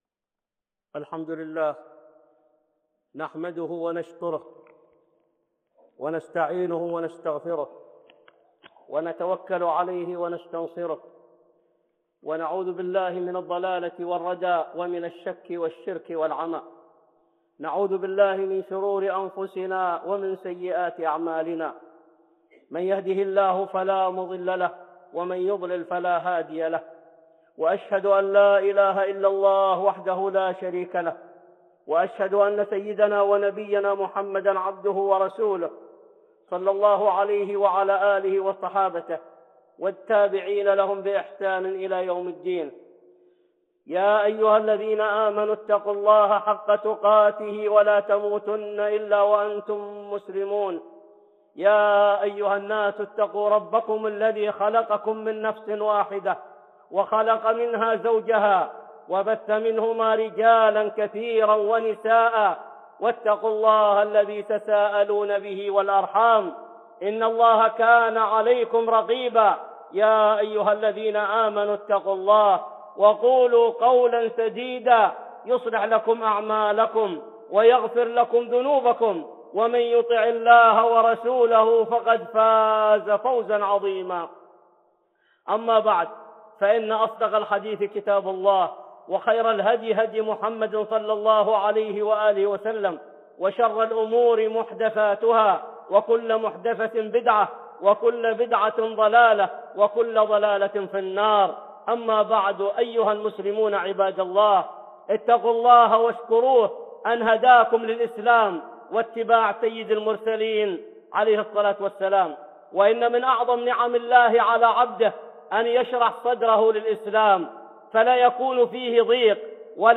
(خطبة جمعة) إنشراح الصدر